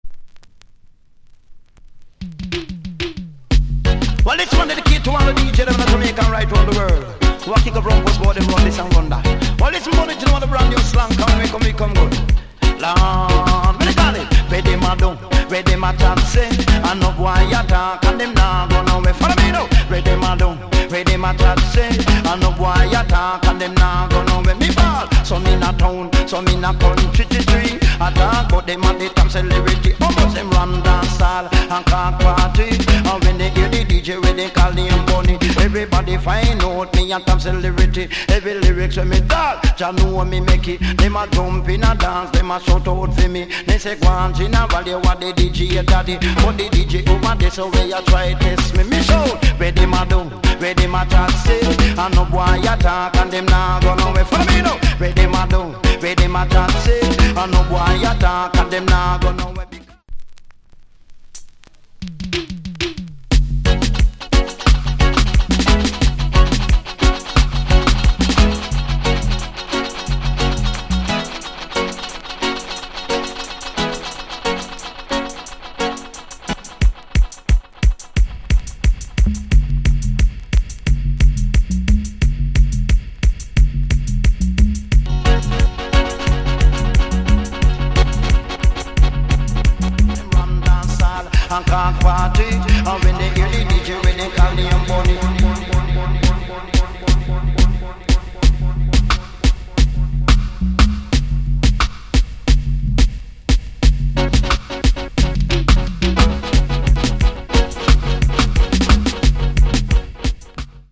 * Late 80's Bad DJ Tune **あいつ等が何しようが何言おうが俺には関係ねえと。